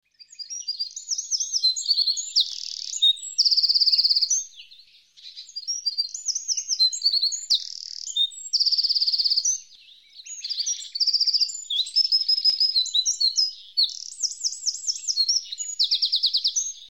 Winterkoning
Winterkoning.mp3